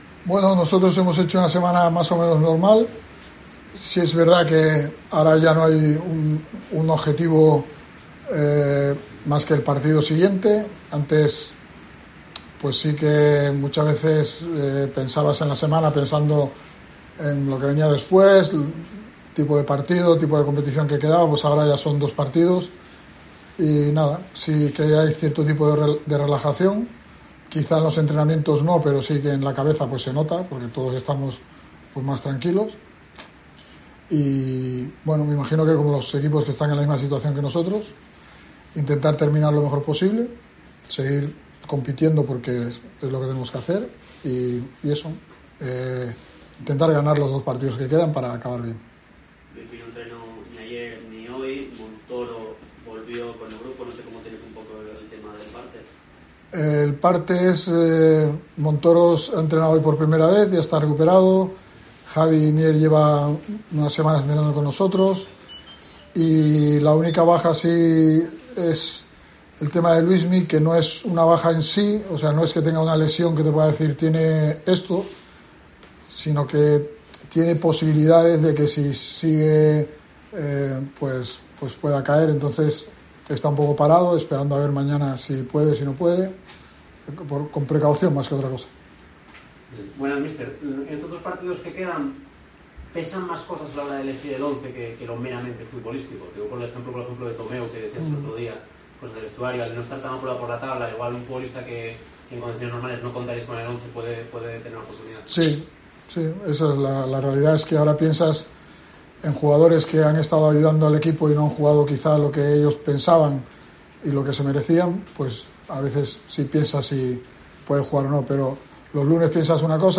Álvaro Cervera ha comparecido ante los medios de comunicación antes de recibir al Racing de Santander en el último partido de la temporada en el Carlos Tartiere, correspondiente a la jornada 41 en LaLiga SmartBank.